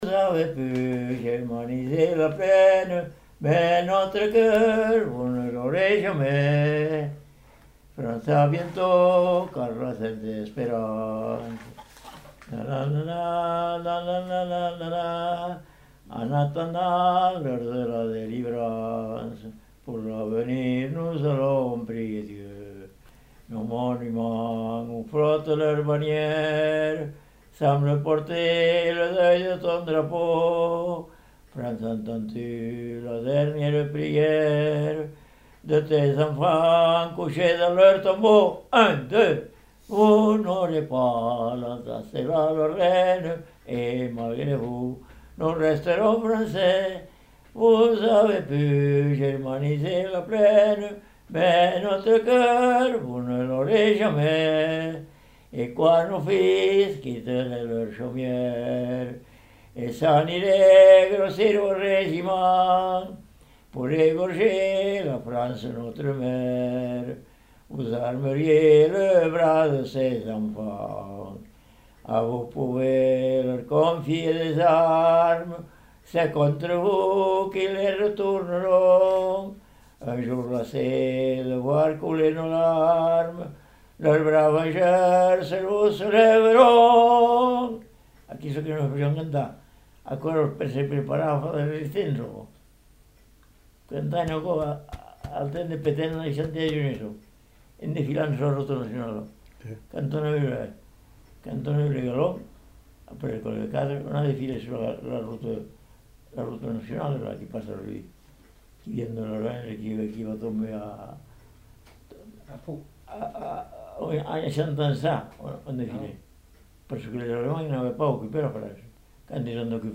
Aire culturelle : Lauragais
Lieu : Caraman
Genre : chant
Effectif : 1
Type de voix : voix d'homme
Production du son : chanté
Classification : chanson patriotique
Notes consultables : Suivi d'une discussion.